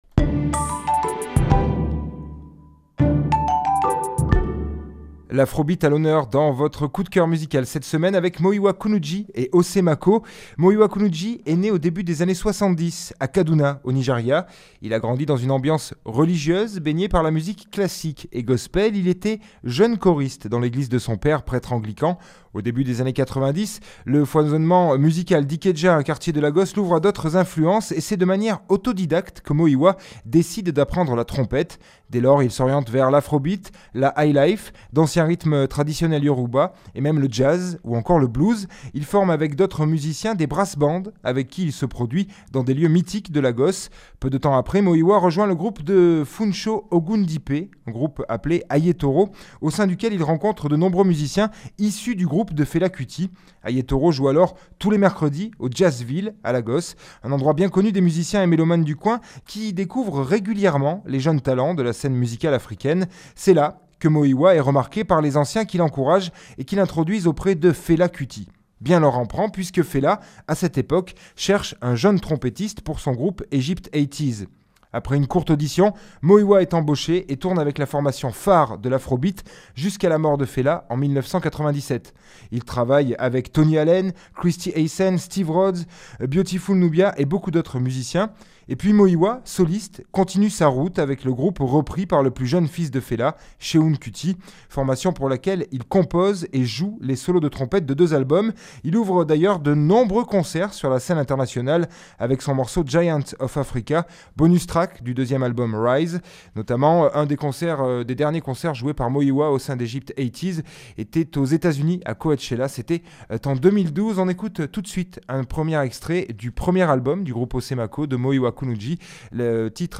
il a été diffusé sur Radio CF Ardeche et RCF Drome